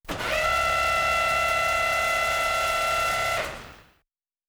pgs/Assets/Audio/Sci-Fi Sounds/Mechanical/Servo Big 7_2.wav at 7452e70b8c5ad2f7daae623e1a952eb18c9caab4
Servo Big 7_2.wav